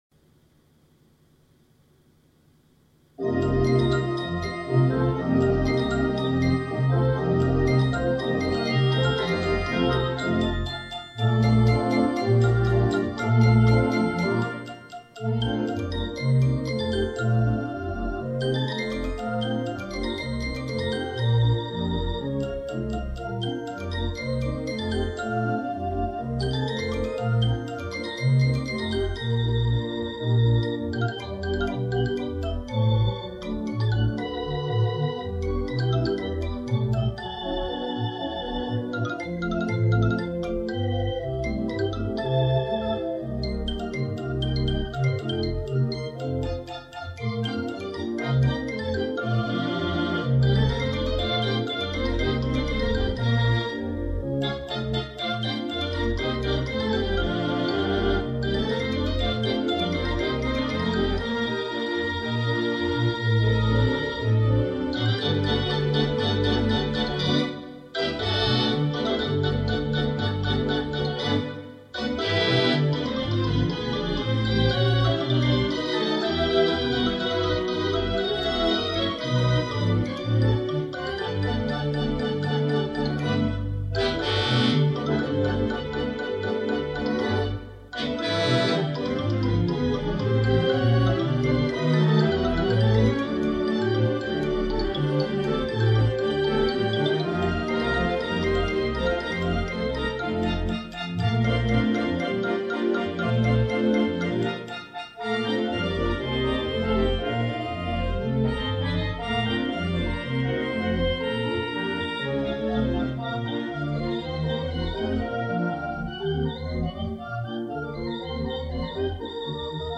theatre organ